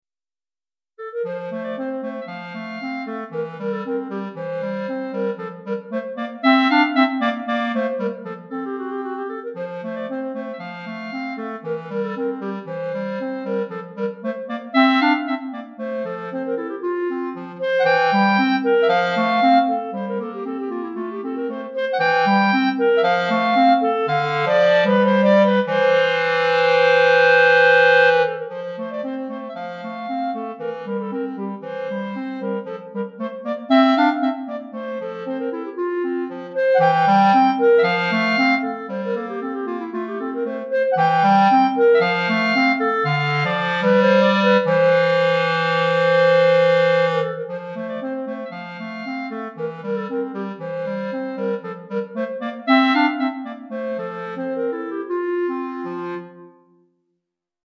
Répertoire pour Clarinette - 2 Clarinettes